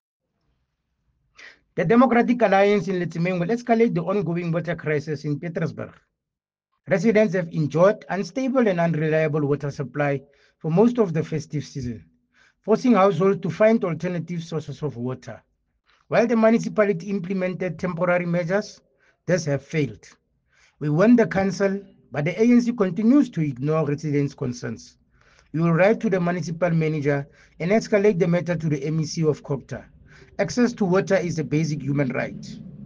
English soundbite by Cllr Thabo Nthapo,